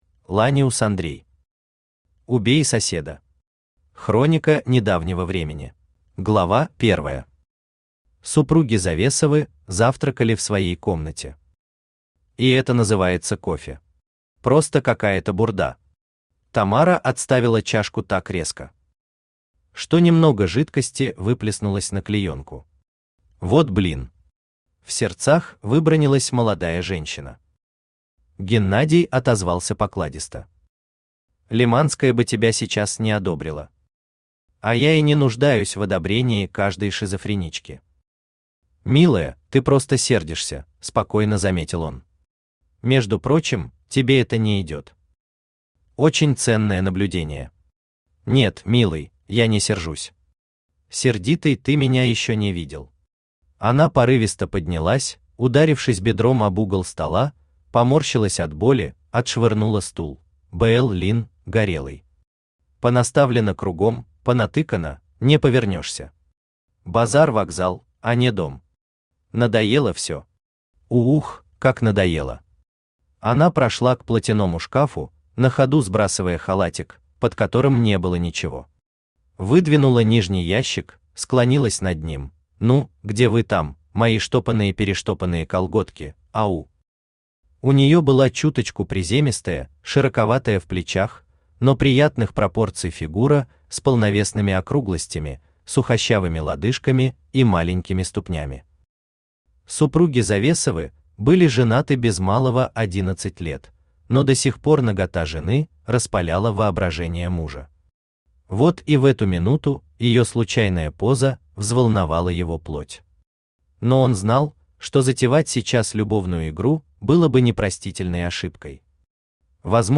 Аудиокнига Убей соседа… Хроника недавнего времени | Библиотека аудиокниг